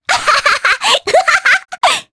Rephy-Vox_Happy3_jp.wav